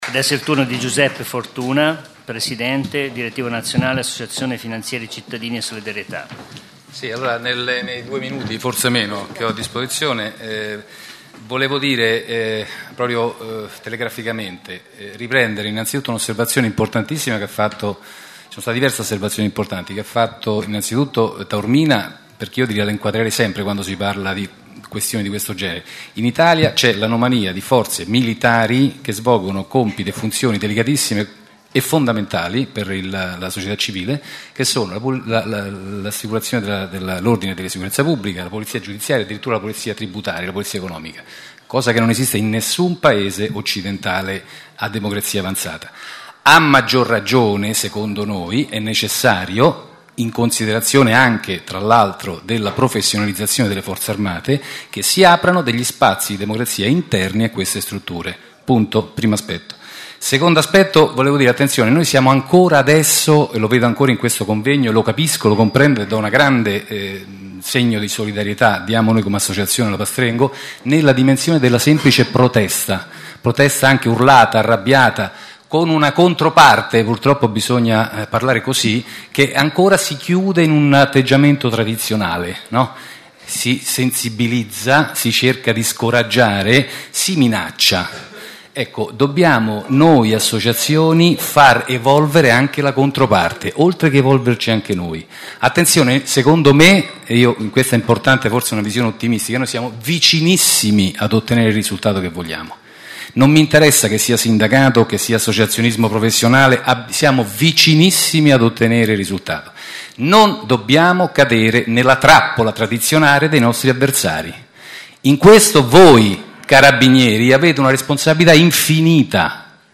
Convegno Pastrengo 9 maggio 2009